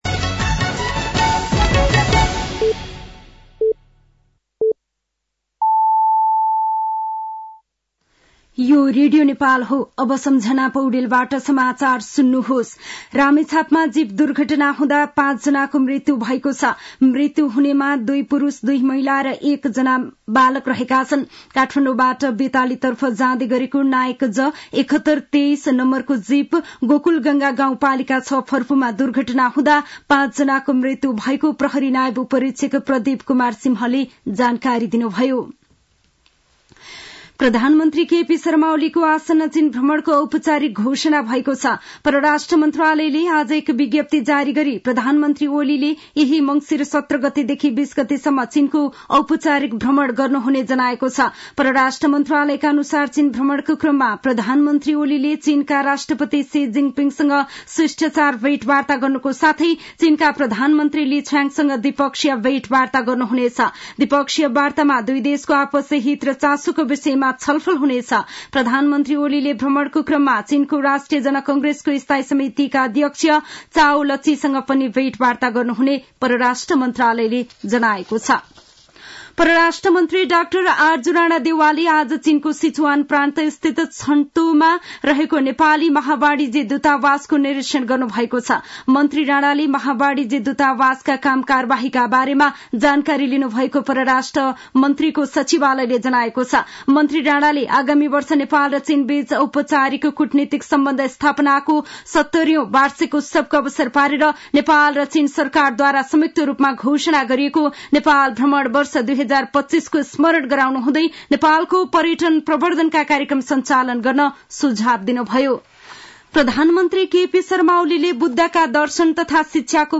साँझ ५ बजेको नेपाली समाचार : १५ मंसिर , २०८१
5-pm-nepali-news-8-14.mp3